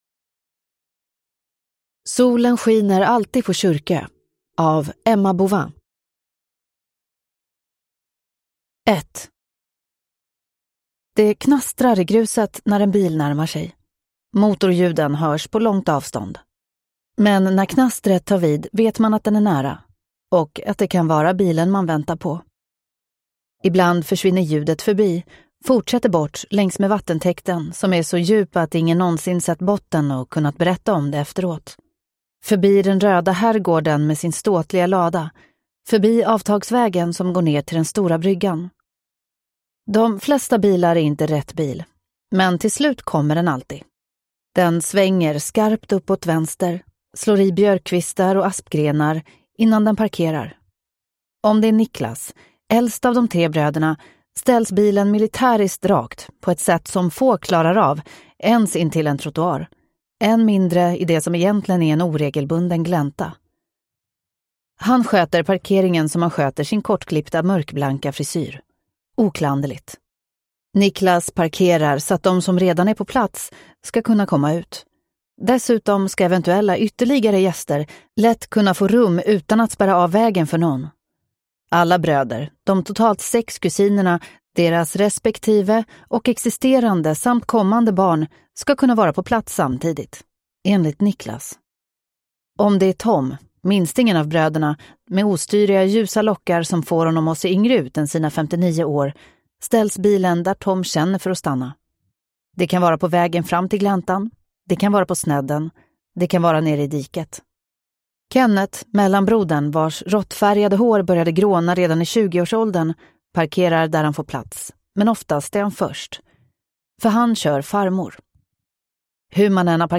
Solen skiner alltid på Tjurkö (ljudbok) av Emma Bouvin